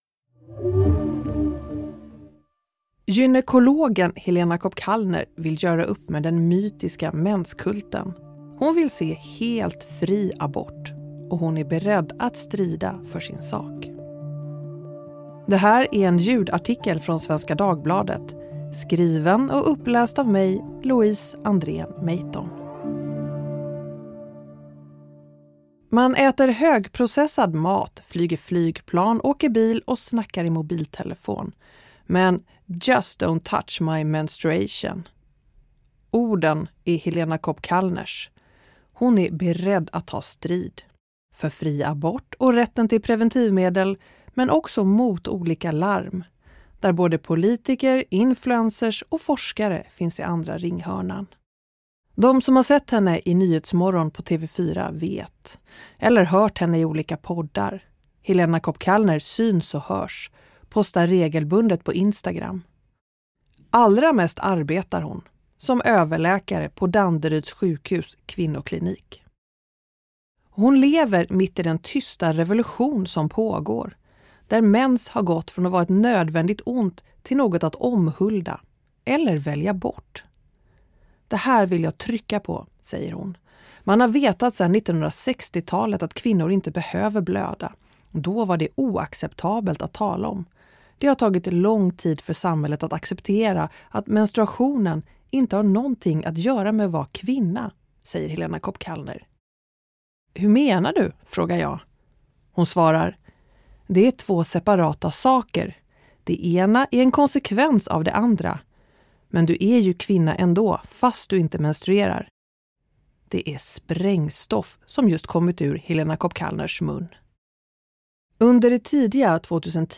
Inläst: Vem vill anställa någon som suttit på rättspsyk?